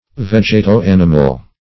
vegeto-animal - definition of vegeto-animal - synonyms, pronunciation, spelling from Free Dictionary
Search Result for " vegeto-animal" : The Collaborative International Dictionary of English v.0.48: Vegeto-animal \Veg"e*to-an"i*mal\, a. (Biol.)